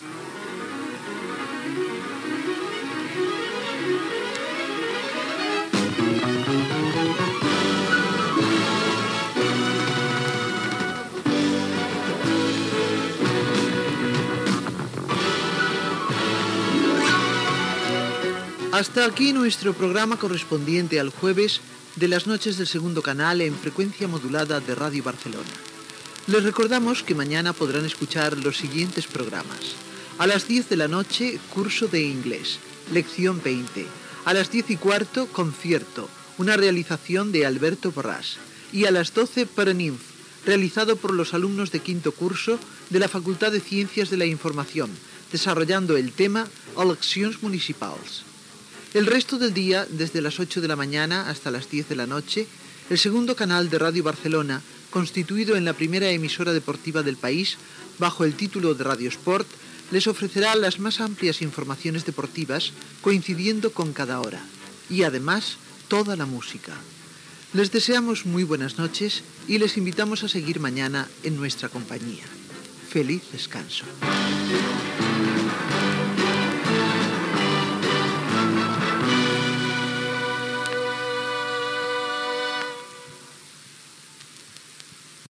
Programació del dia següent, amb esment a la radiofórmula "Radio Sport" i tancament de l'emissió
FM